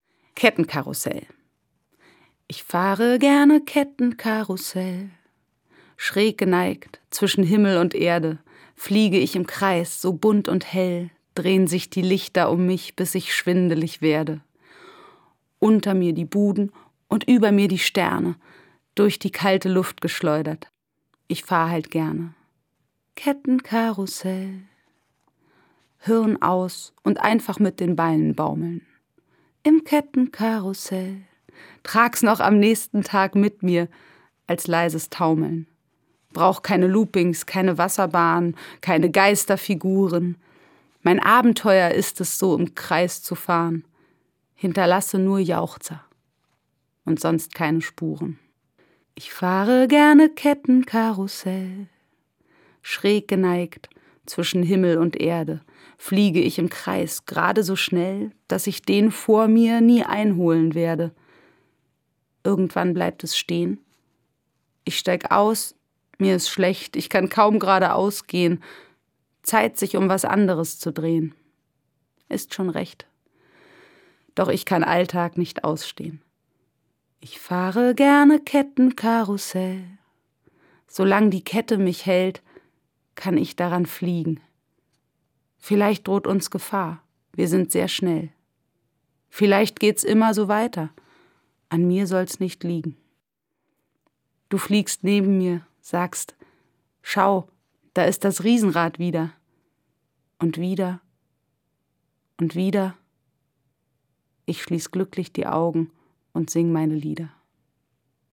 Das radio3-Gedicht der Woche: Dichter von heute lesen radiophone Lyrik.
Gelesen und gesungen von Dota Kehr.